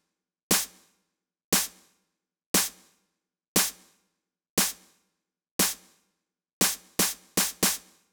28 Snare PT2.wav